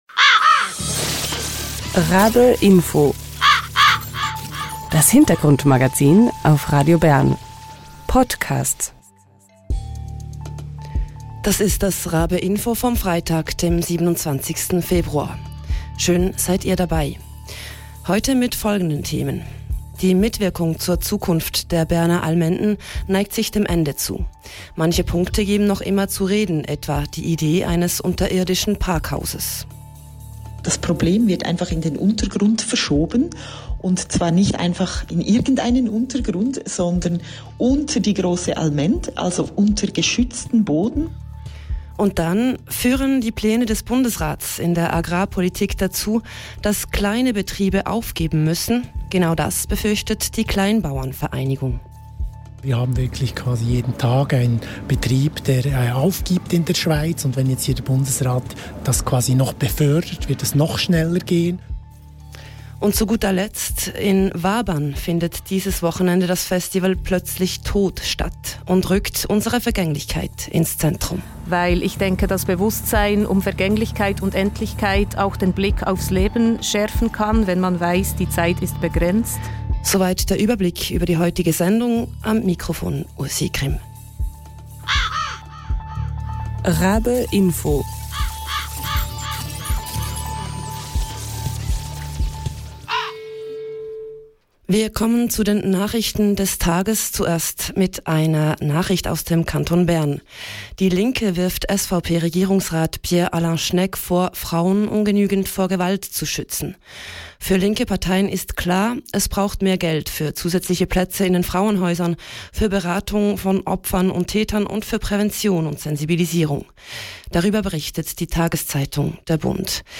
Genau das befürchtet die Kleinbauern-Vereinigung. Im Interview hören wir, weshalb.
Im Talk hören wir, wer dahinter steht.